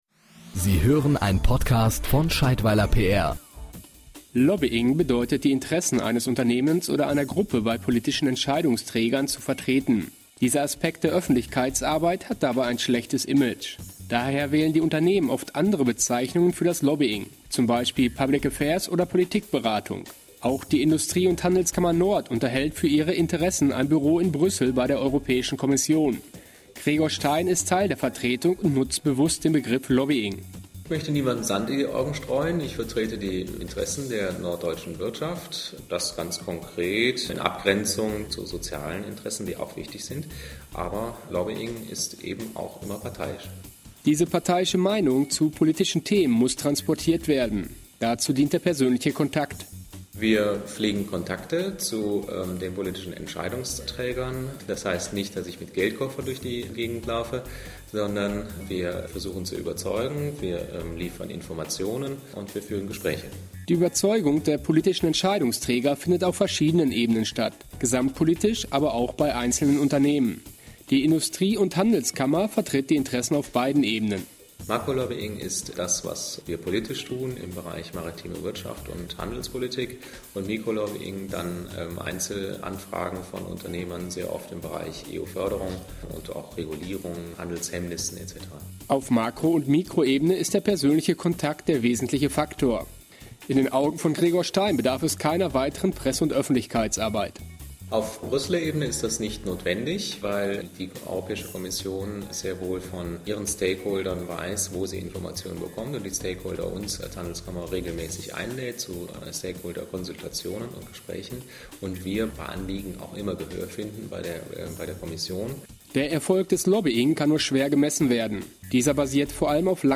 PR-Podcast: Lobbying der IHK Nord in Brüssel, Interview